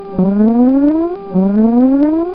sfx_warning.mp3